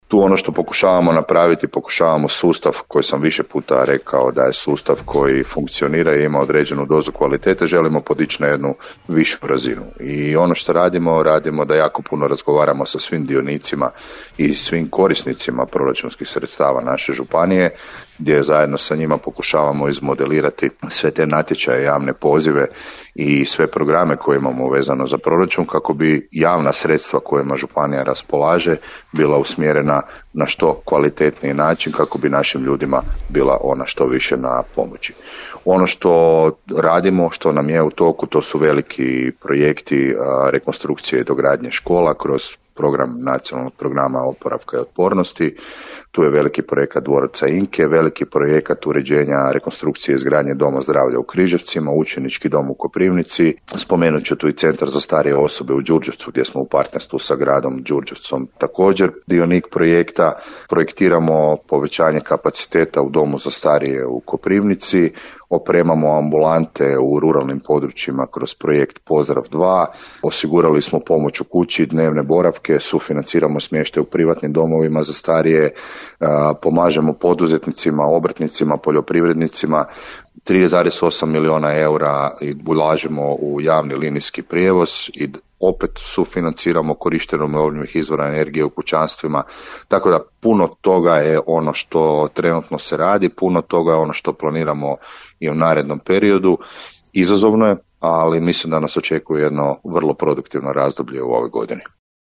Gostujući u emisiji „Aktualnosti iz županije” župan Tomislav Golubić istaknuo je kako je proračun prvenstveno razvojno orijentiran te usmjeren na konkretne programe i projekte koji će imati vidljiv utjecaj na kvalitetu života građana.